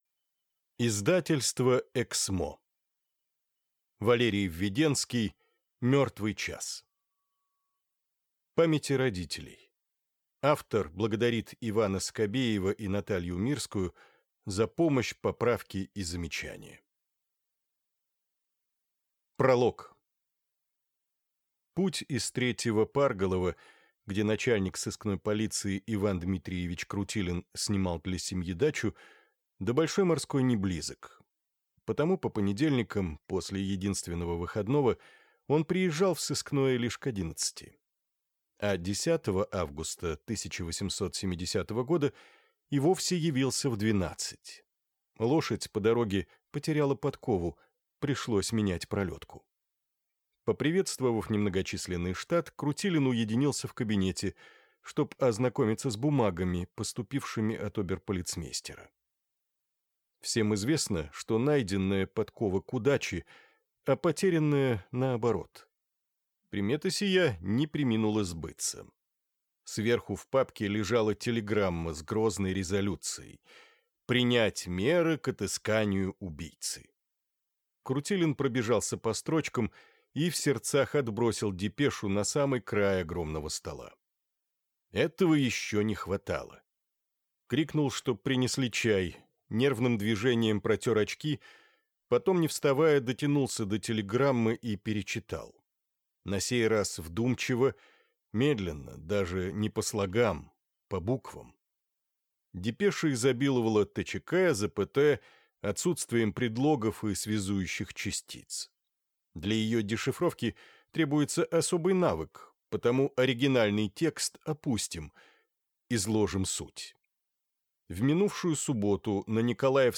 Аудиокнига Мертвый час | Библиотека аудиокниг